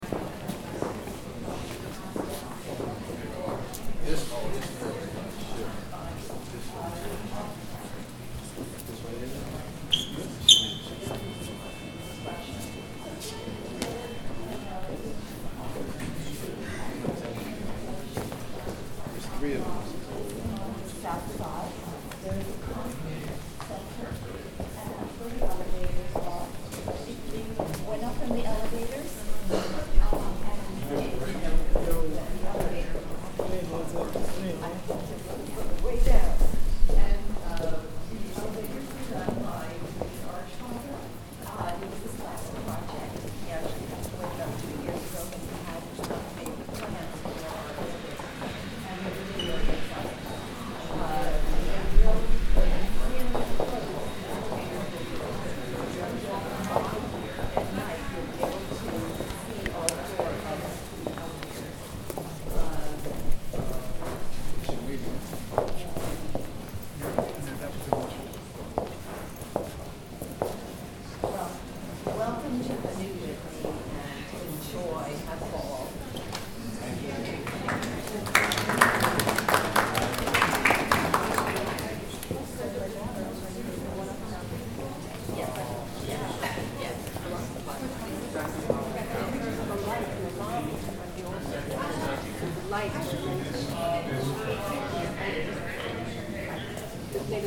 Zvuky: New York
New York, znovuotevřené Whitney Museum
023-New-York-Whitney-Museum.mp3